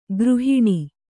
♪ gřhiṇi